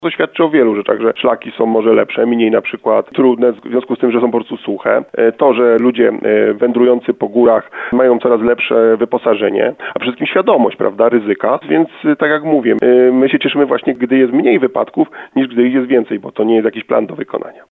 Mniej interwencji w górach niż zazwyczaj. Jednak GOPR cały czas apeluje o rozwagę [ROZMOWA]